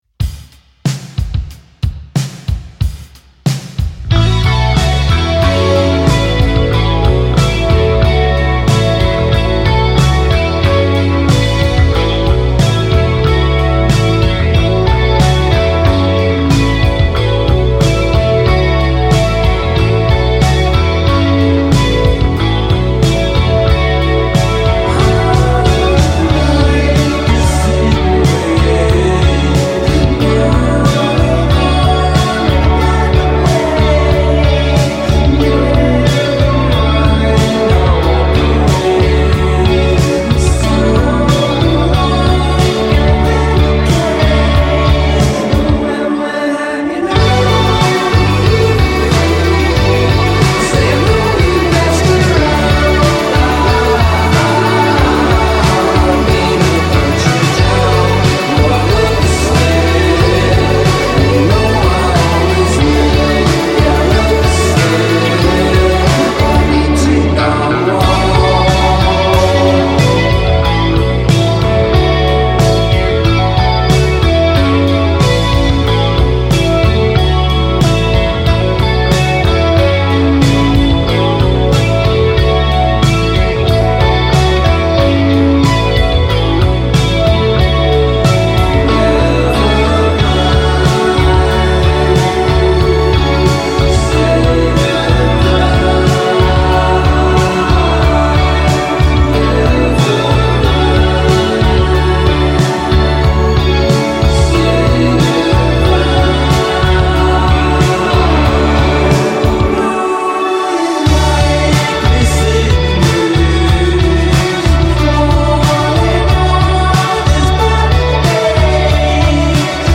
the dreamy four-piece from Newcastle